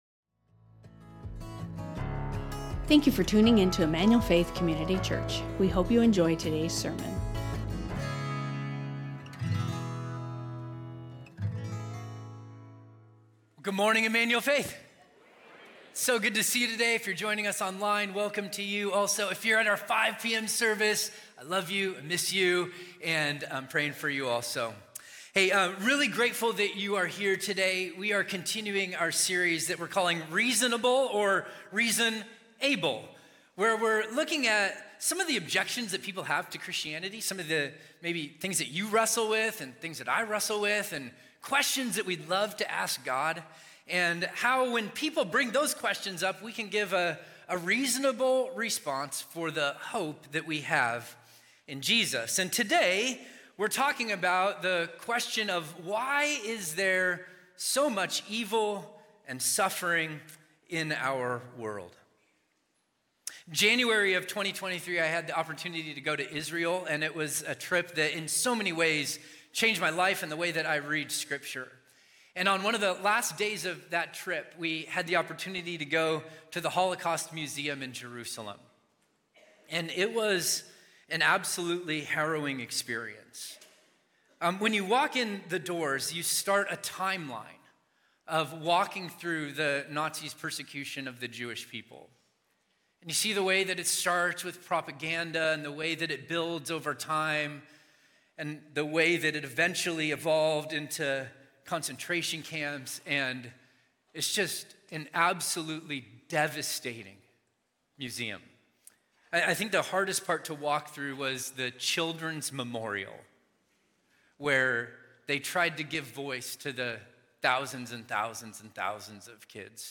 Emmanuel Faith Sermon Podcast